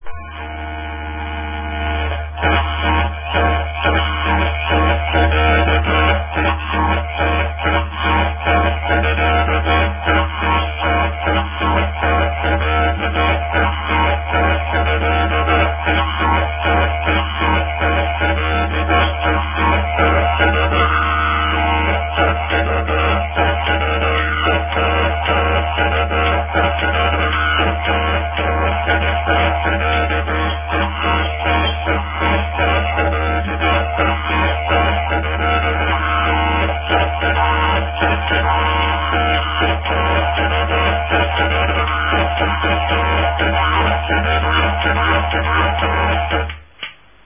Traditional Didjeridu
Aborigine songs, with some didjeridu.